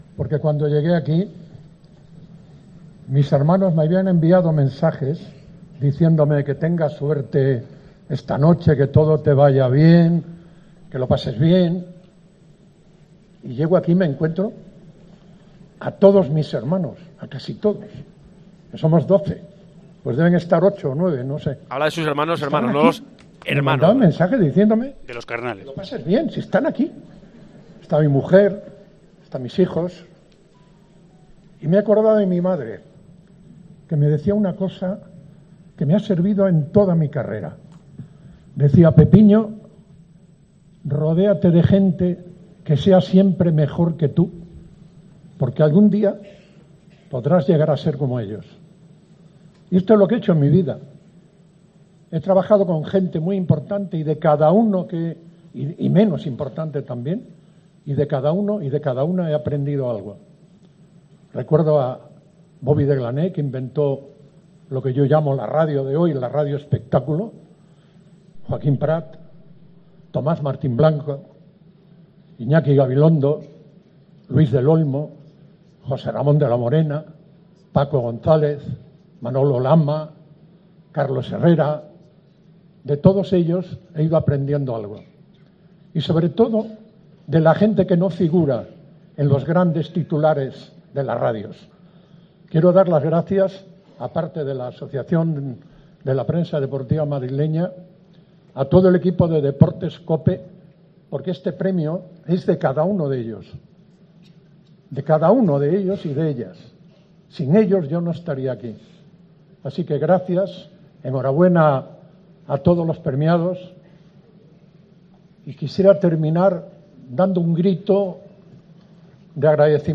Escucha el discurso de Pepe Domingo Castaño, tras recibir un nuevo premio, en la gala de los 'Premios anuales del deporte 2018' que entrega la APDM.